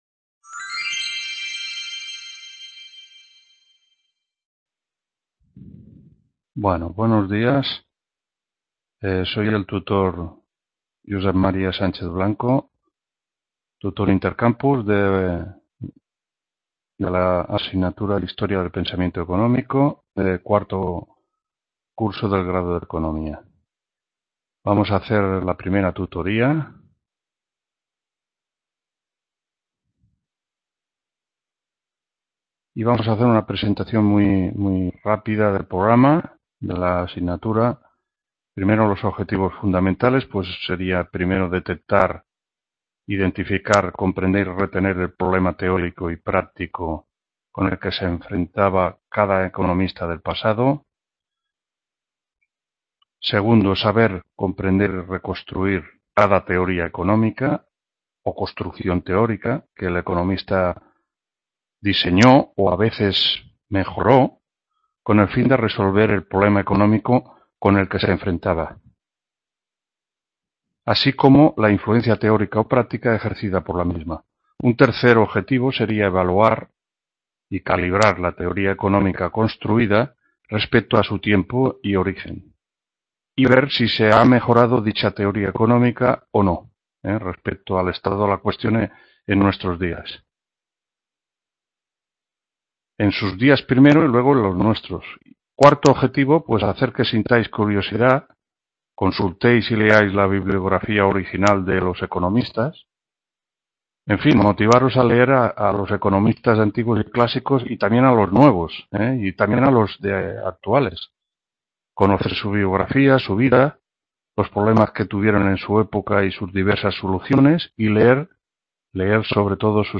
1ª TUTORÍA HISTORIA DEL PENSAMIENTO ECONÓMICO 14-15… | Repositorio Digital